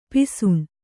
♪ pisuṇ